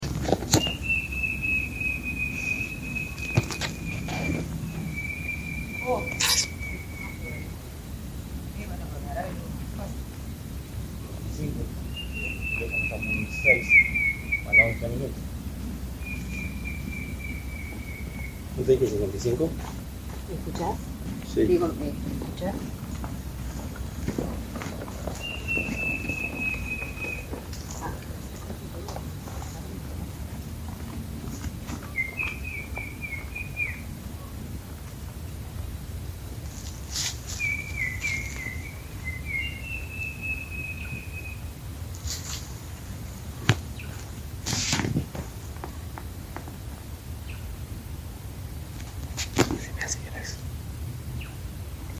White-shouldered Fire-eye (Pyriglena leucoptera)
Location or protected area: Parque Nacional Iguazú
Condition: Wild
Certainty: Recorded vocal
Batara-negro.mp3